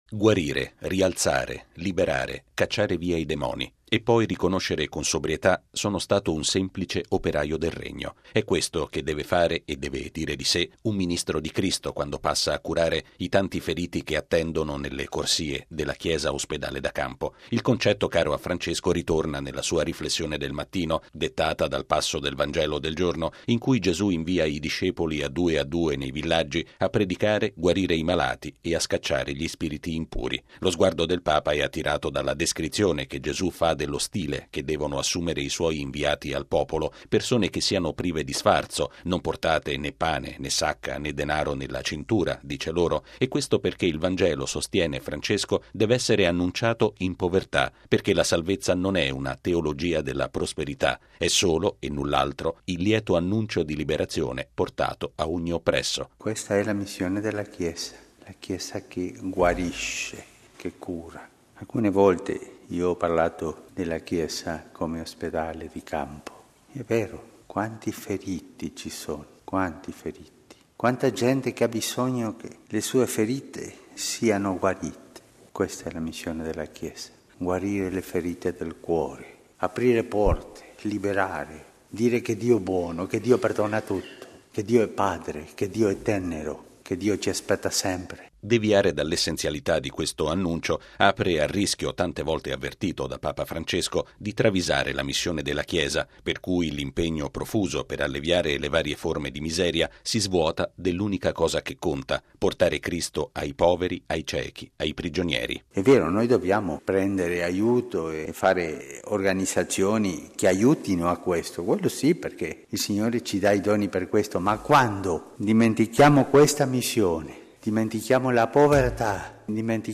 La Chiesa deve annunciare il Vangelo “in povertà” e chi lo annuncia deve avere come unico obiettivo quello di alleviare le miserie dei più poveri, senza mai dimenticare che questo servizio è opera dello Spirito Santo e non di forze umane. È il pensiero di fondo dell’omelia che Papa Francesco ha tenuto nella Messa celebrata in Casa Santa Marta.